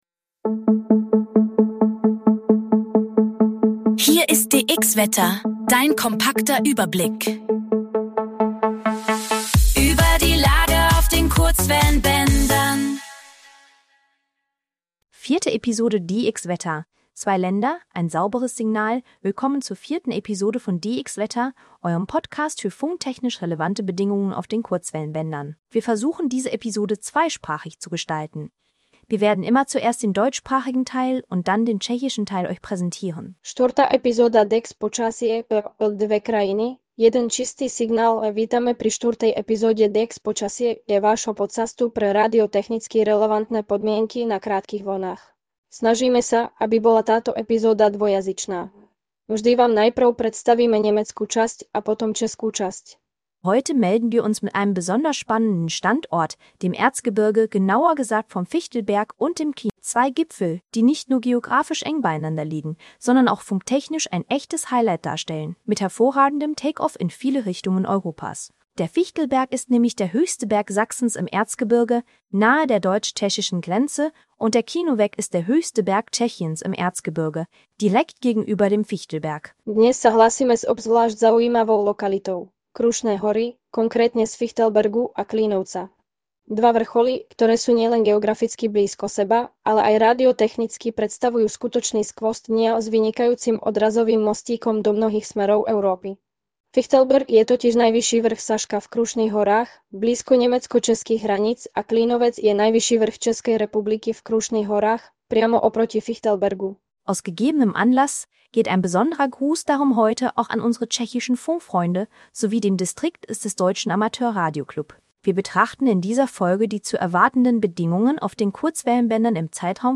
KI-generierter Inhalt - aktuell für die Region D-Fichtelberg und